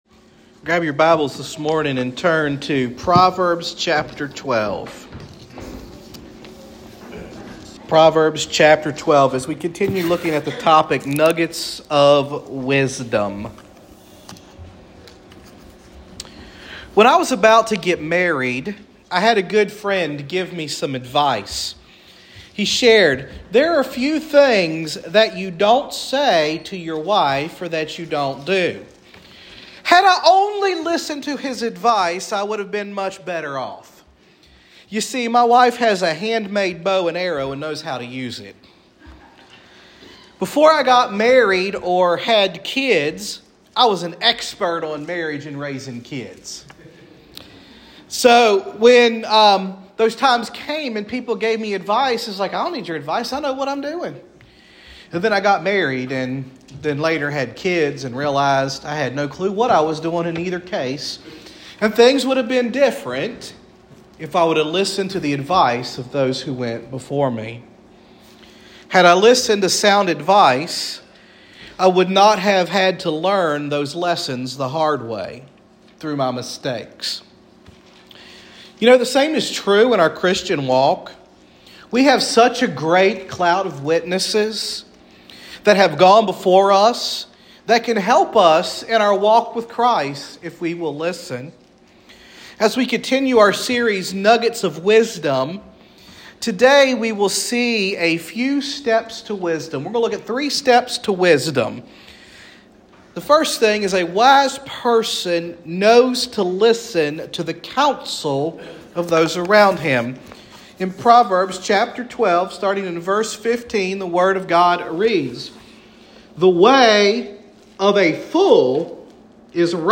Sermons | Hopewell First Baptist Church